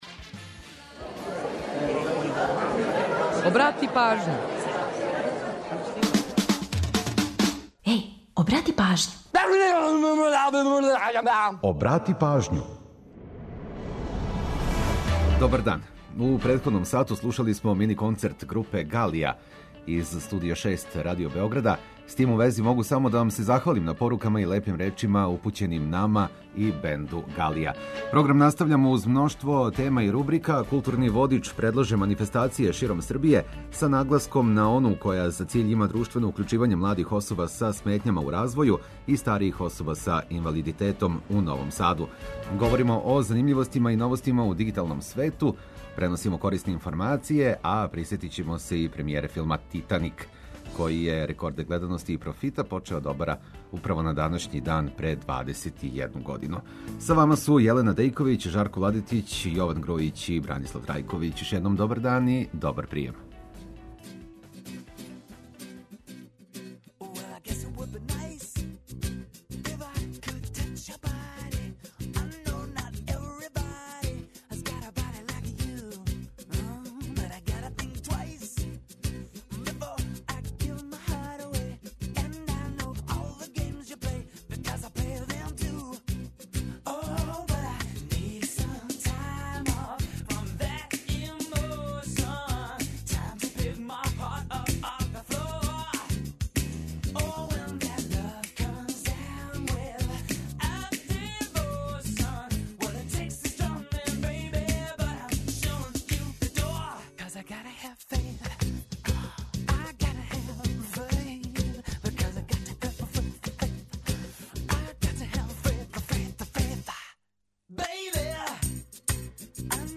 Када група Галија заврши мини концерт у чувеном Студију 6, програм настављамо уз мноштво тема и рубрика.